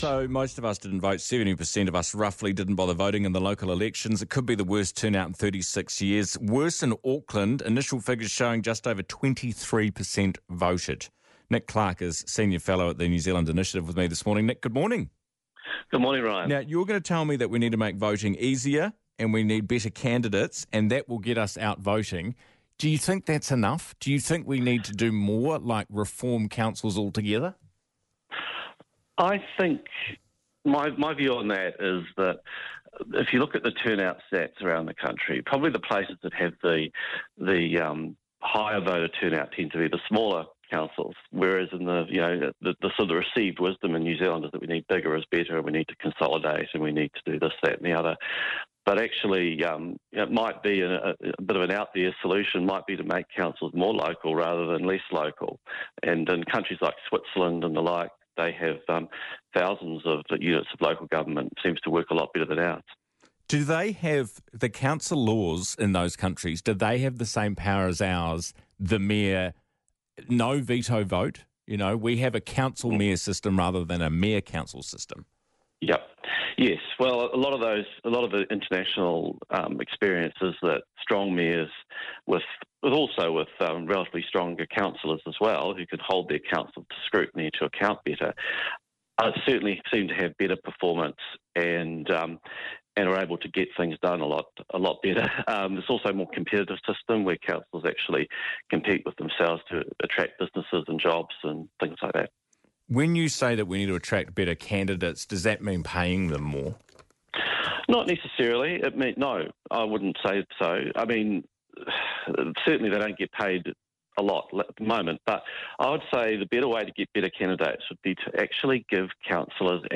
talked to Ryan Bridge on Newstalk ZB